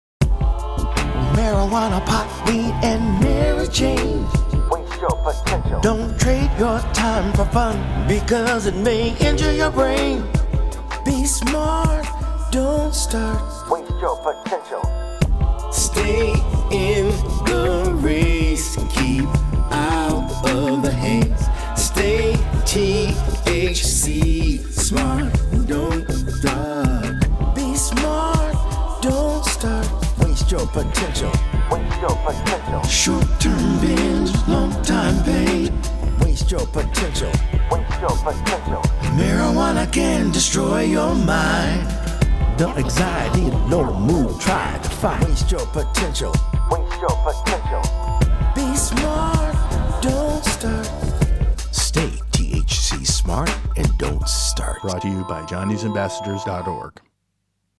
Television and Radio PSA Ads Free For You to Use!
RADIO